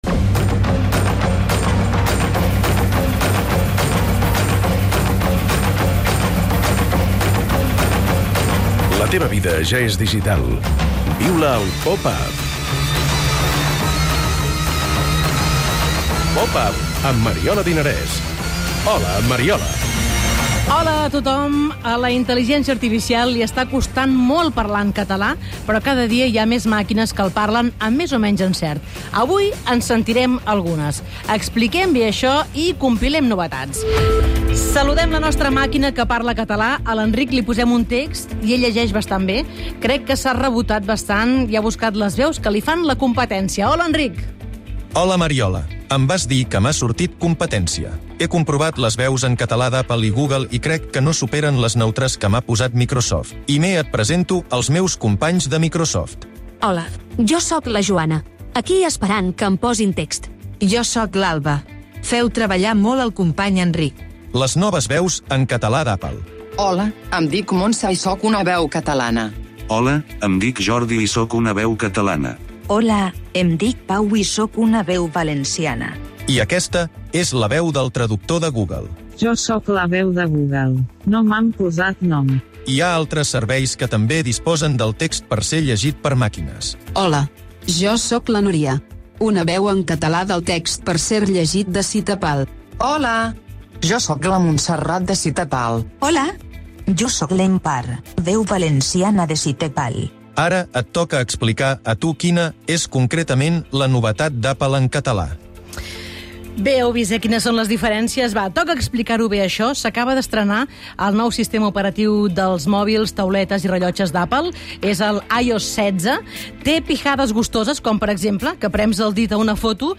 Beschreibung vor 3 Jahren Comparem les noves veus en català d'Apple amb les de Microsoft, Google i SitePal. Fem la crònica del FIBA 3x3, un esdeveniment organitzat per Ibai Llanos i transmès a Twitch.